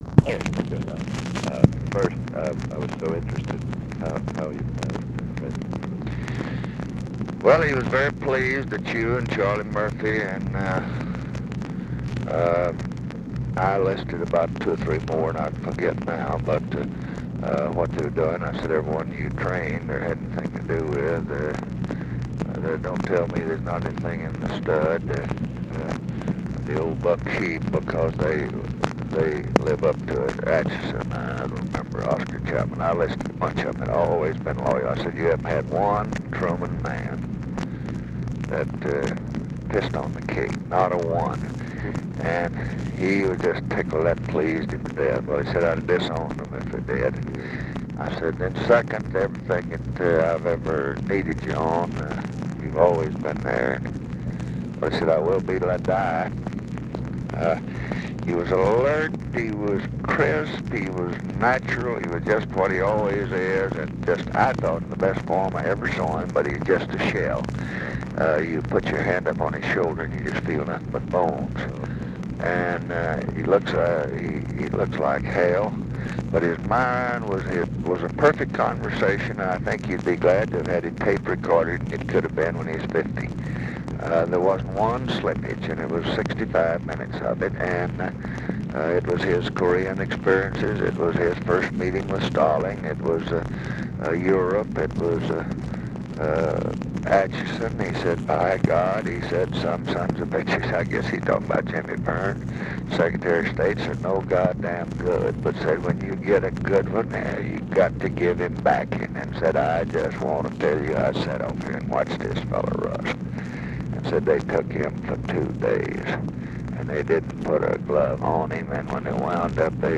Conversation with CLARK CLIFFORD, May 4, 1968
Secret White House Tapes